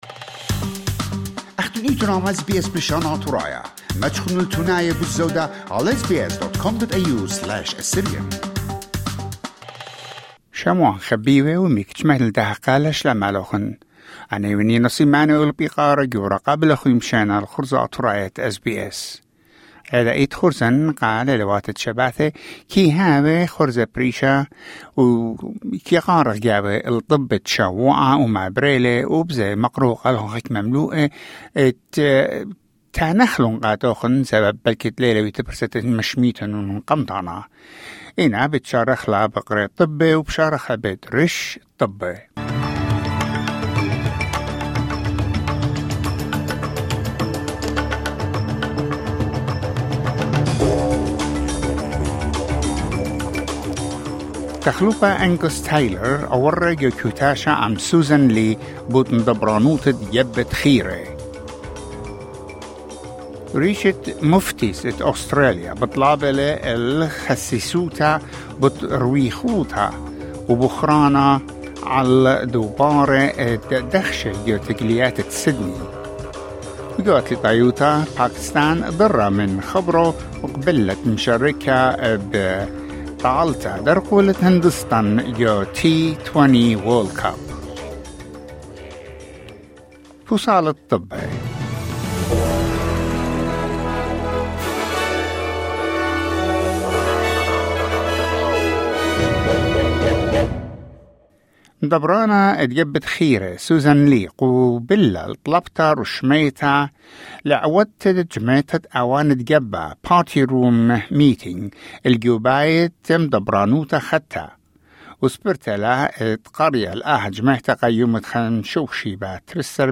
Weekly news wrap